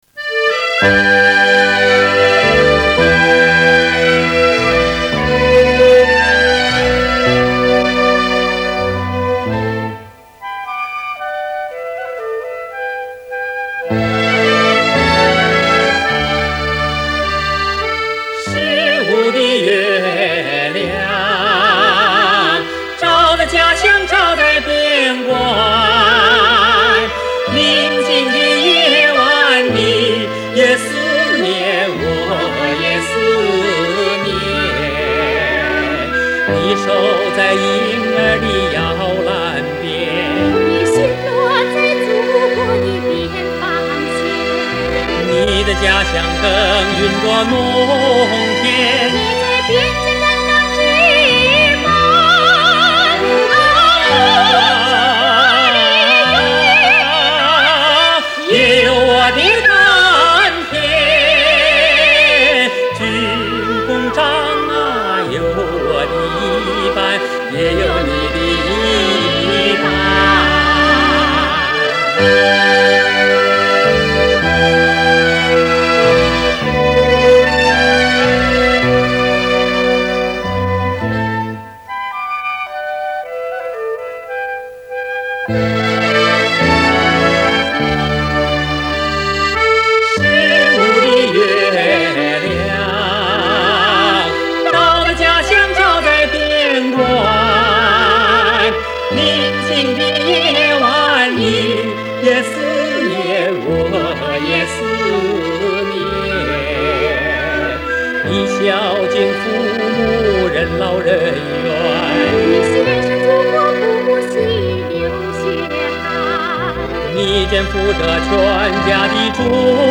他有着抒情男高音俊朗优美的声线，坚持以情带声的歌唱原则，把情歌唱得清爽明澈，把颂歌唱得亲切得体。